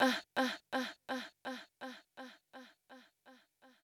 House / Voice / VOICEGRL101_HOUSE_125_A_SC2(R).wav